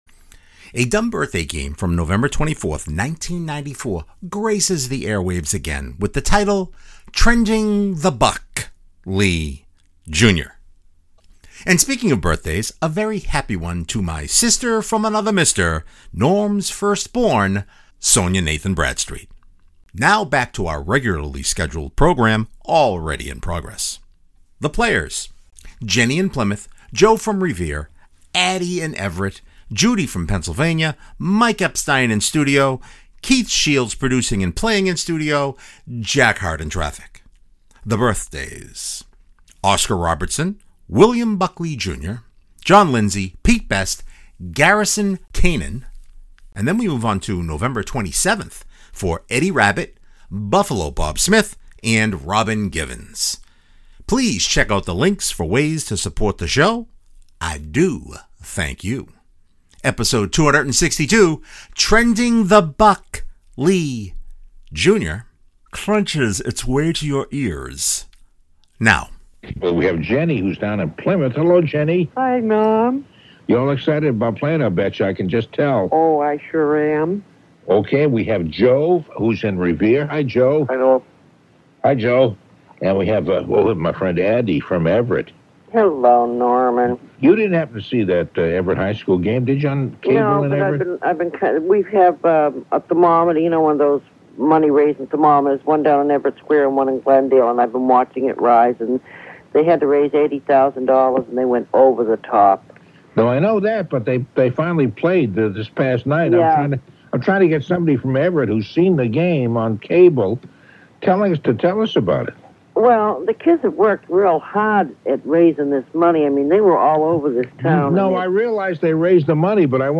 Now back to our regularly scheduled program already in progress.